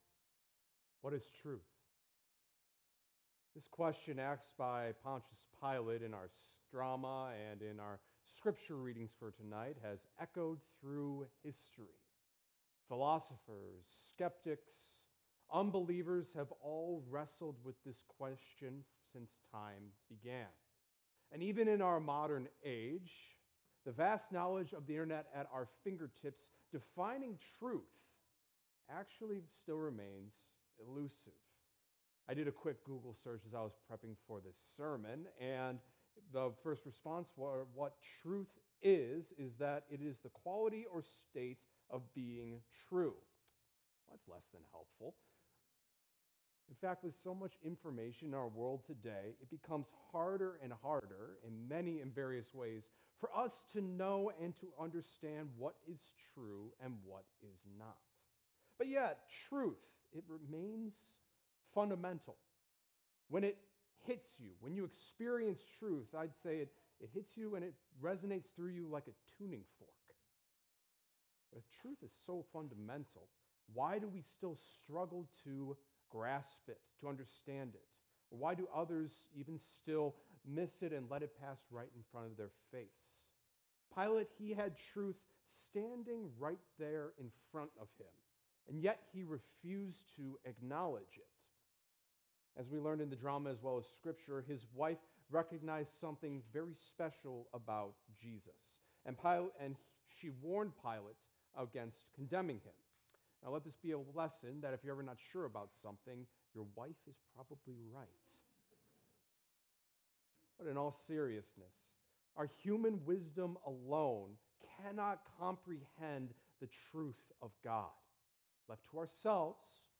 Sermons by Immanuel Crystal Lake